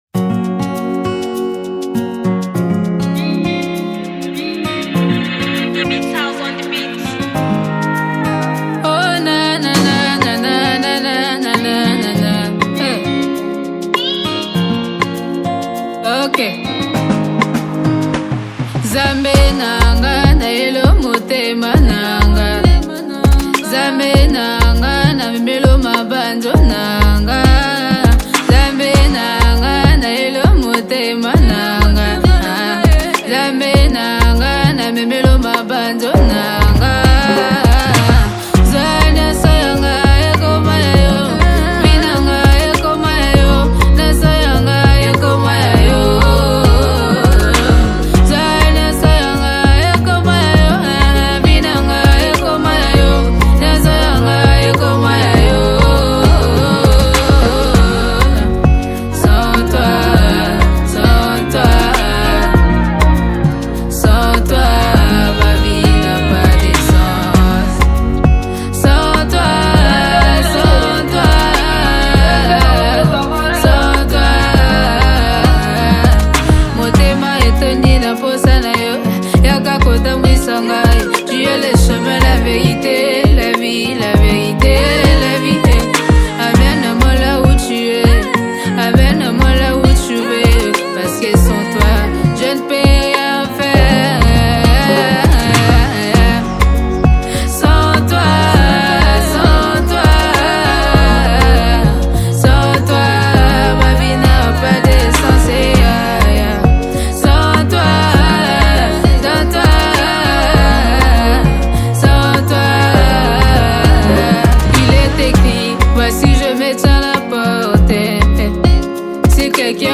Congo Gospel Music